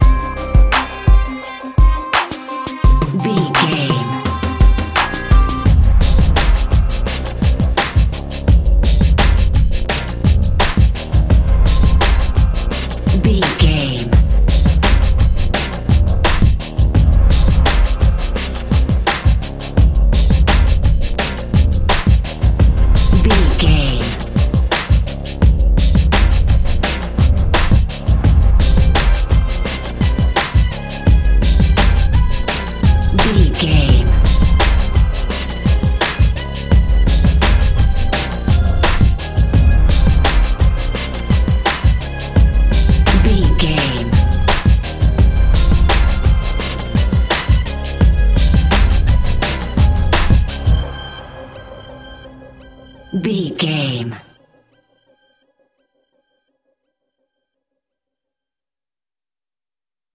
Aeolian/Minor
D
drum machine
synthesiser
hip hop
Funk
neo soul
acid jazz
energetic
bouncy
funky
hard hitting